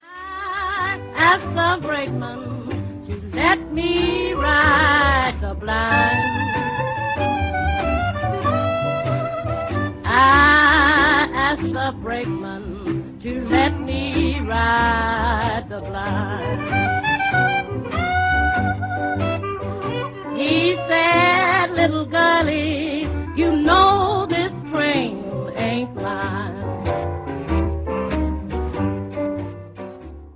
вокал
кларнет
труба
фортепиано
гитара
контрабас
ударные